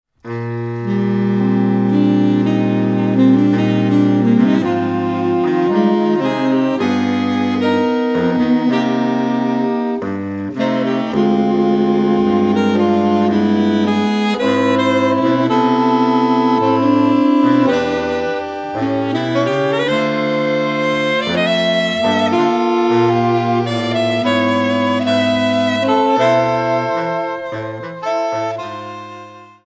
4 Saxophones (SATB)